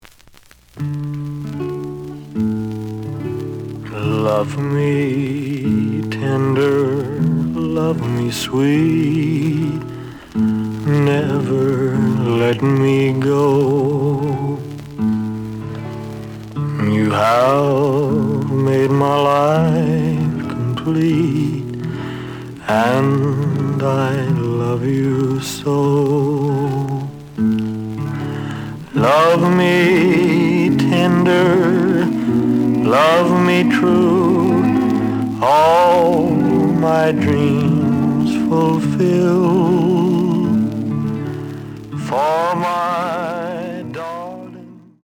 The audio sample is recorded from the actual item.
●Genre: Rhythm And Blues / Rock 'n' Roll
Looks good, but slight noise on both sides.)